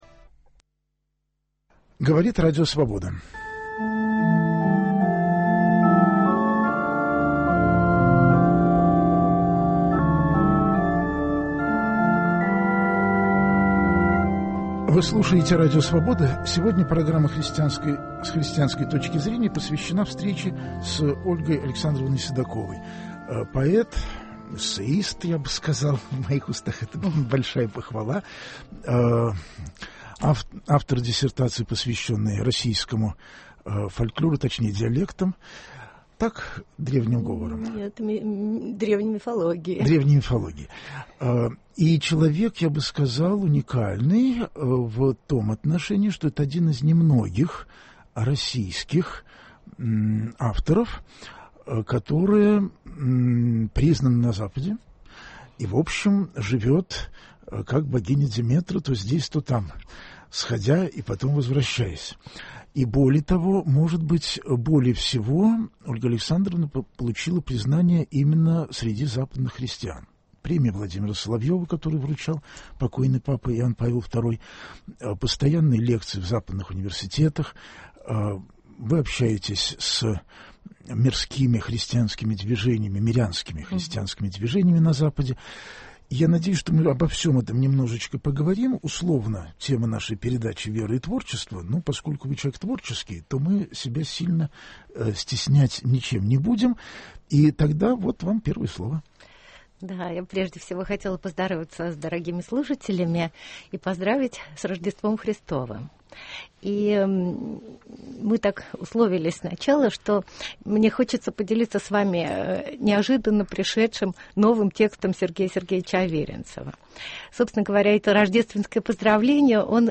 В гостях у программы - поэт и эссеист Ольга Александровна Седакова. Разговор пойдет о вере, свободе и творчестве.